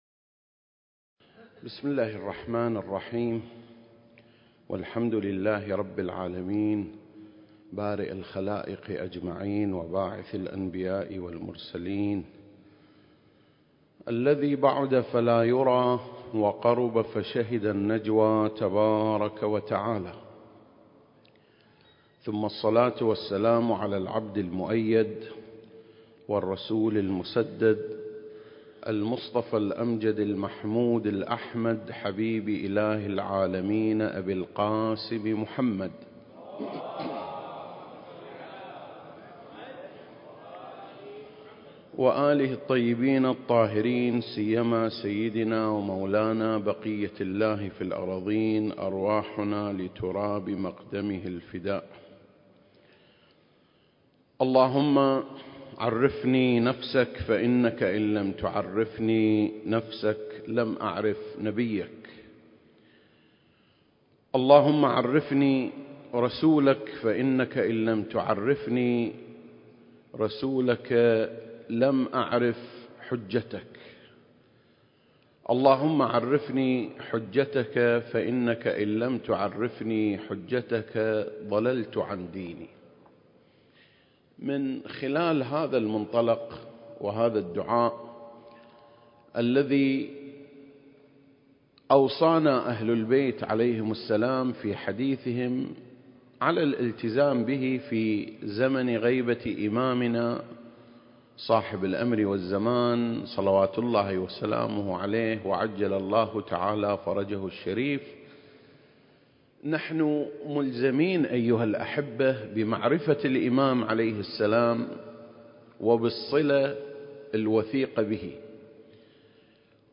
سلسلة: شرح زيارة آل ياسين (13) - قصة التوبيخ (1) المكان: مسجد مقامس - الكويت التاريخ: 2021